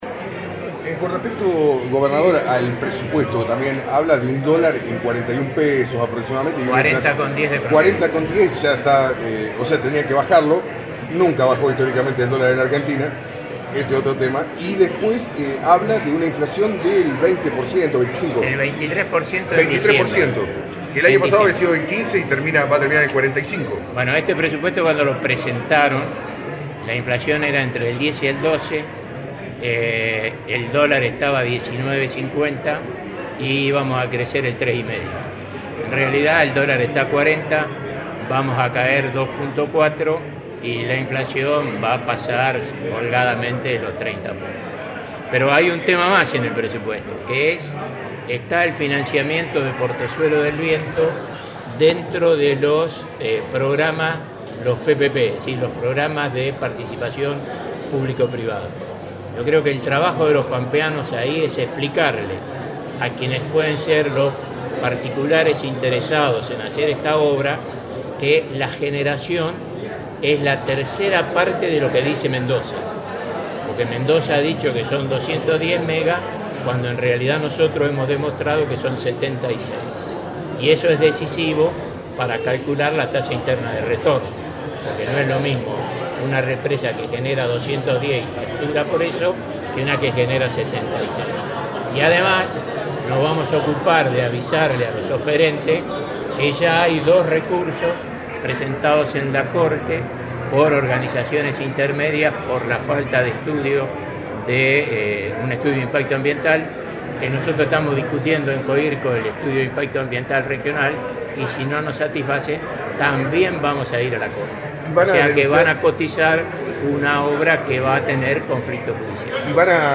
En la conferencia brindada ayer, el Gobernador pampeano se refirió al Presupuesto 2019 donde tambièn se encuentra el financiamiento de Portezuelo del Viento, dentro de los Programas de Participación Público Privada.